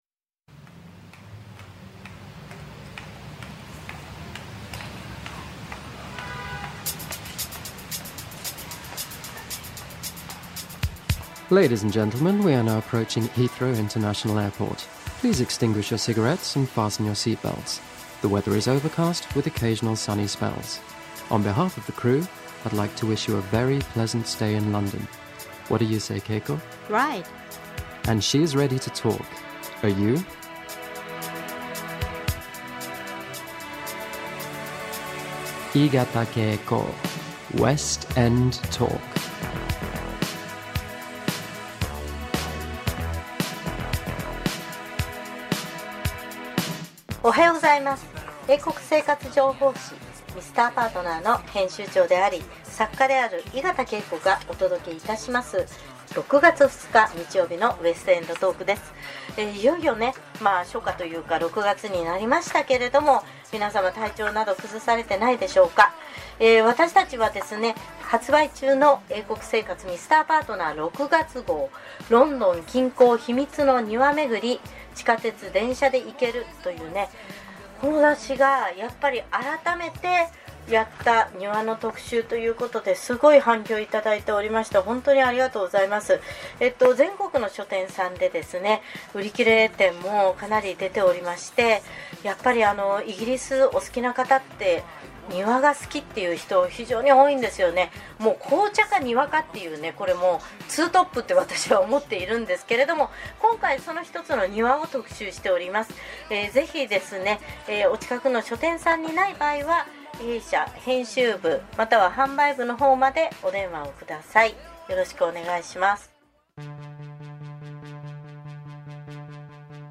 FMラジオ出演の収録音声、おとどけです。
※再生後、2：36頃からコーナー出演です。（一部編集済み）